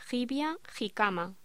Locución: Jibia jicama
voz